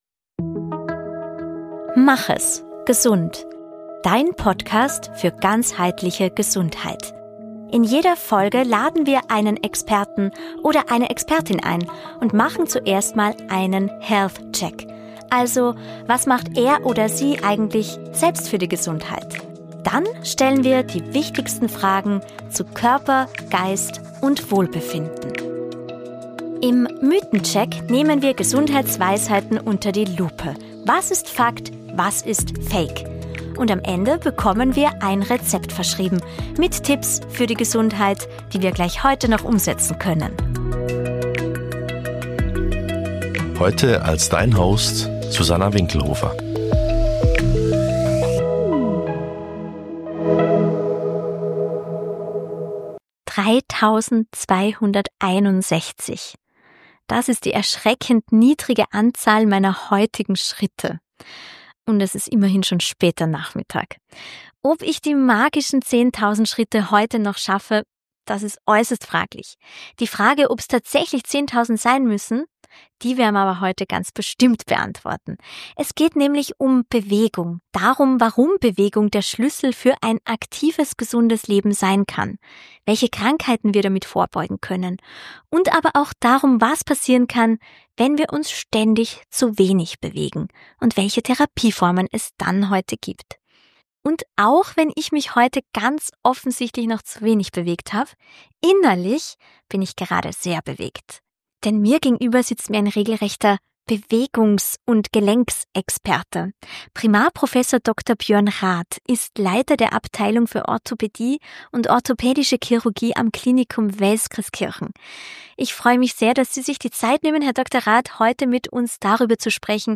Es ist ein Gespräch, das nicht belehrt, sondern berührt.